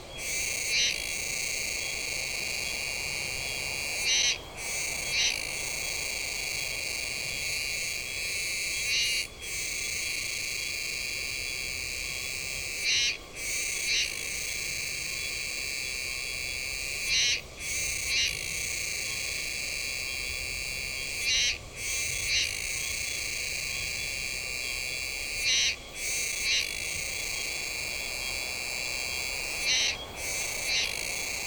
クロイワツクツクの鳴き声
ジワッジージワッジージワッジー、よく秋に鳴き声を聞きます。
＊ 沖縄の動物・植物達のコーナー 録音：SonyリニアPCMレコーダーPCM-M10 恩納村にて録音
kuroiwatsukutsuku-call.mp3